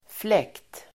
Uttal: [flek:t]